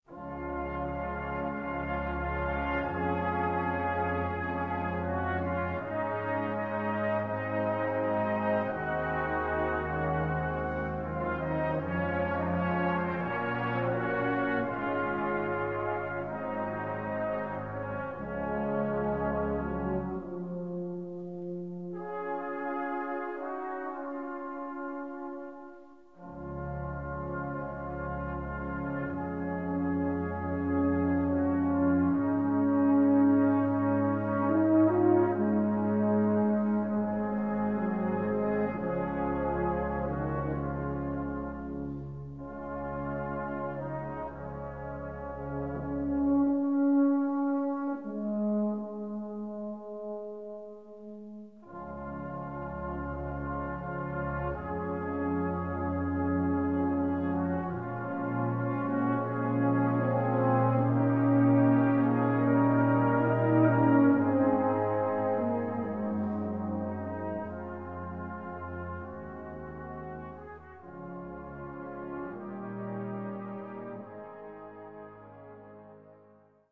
Gattung: Weihnachtsmusik
Besetzung: Blasorchester